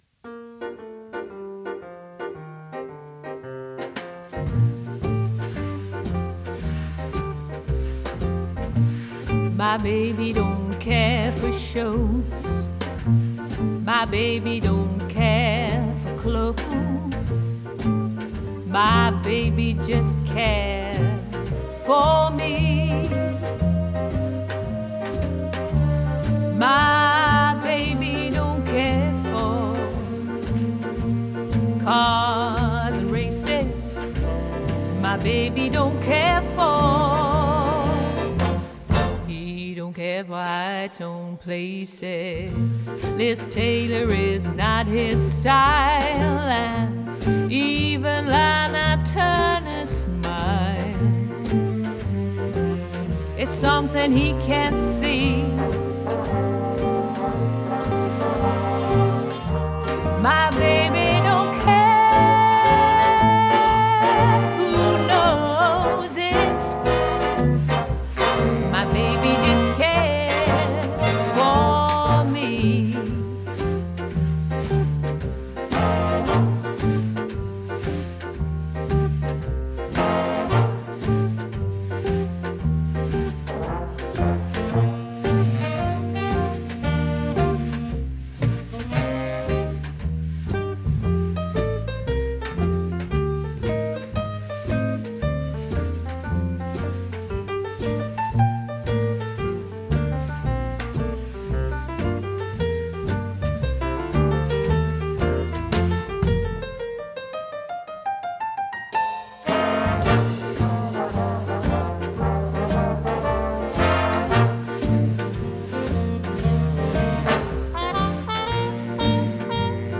Pop/easylistening